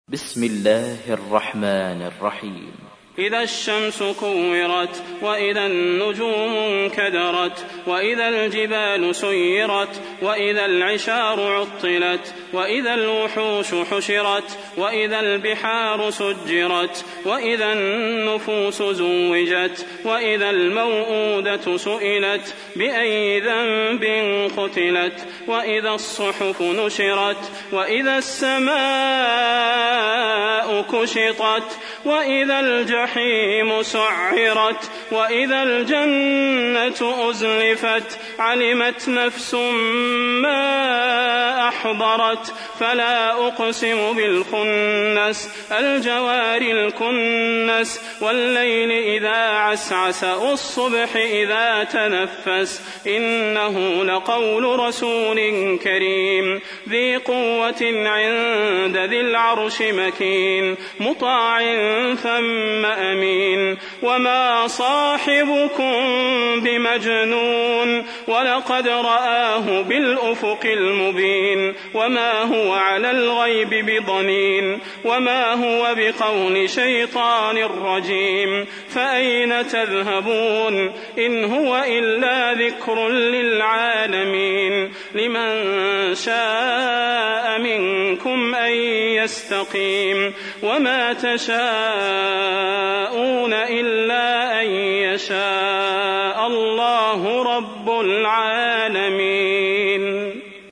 تحميل : 81. سورة التكوير / القارئ صلاح البدير / القرآن الكريم / موقع يا حسين